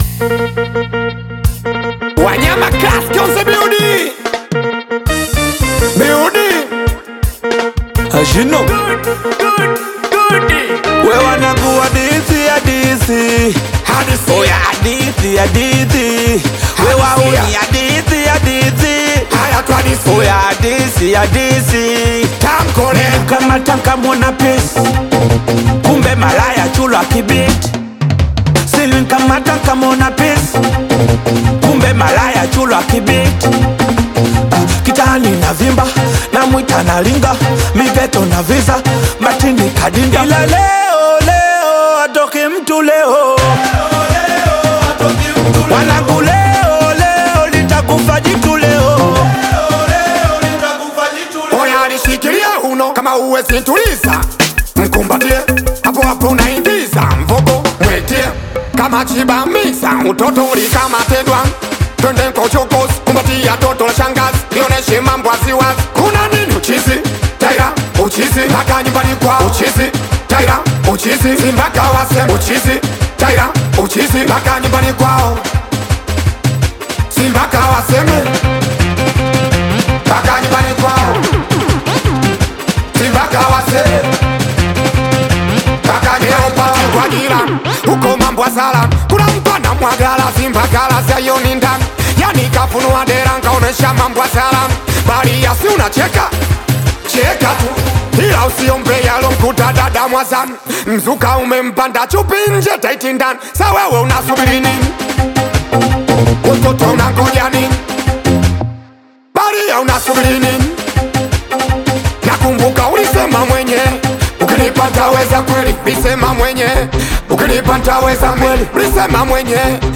high-energy Tanzanian Singeli remix
Genre: Singeli